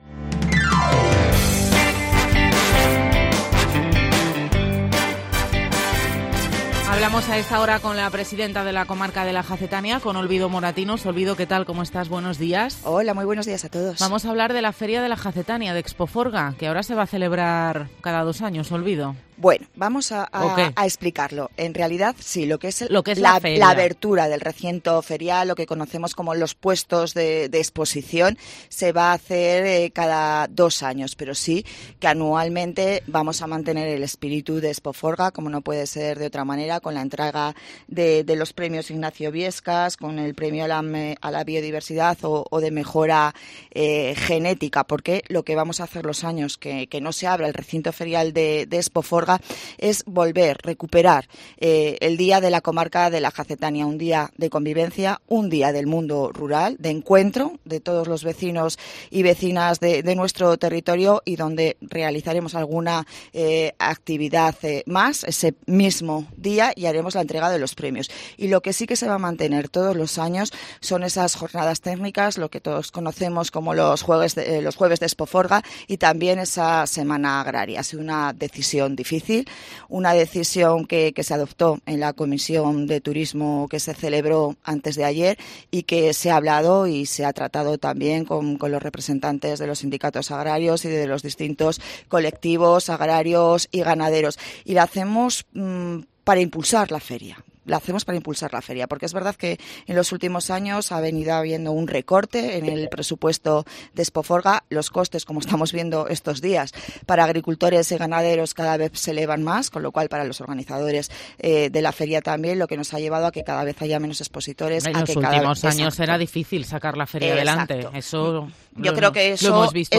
Olvido Moratinos, presidenta de la Comarca de la Jacetania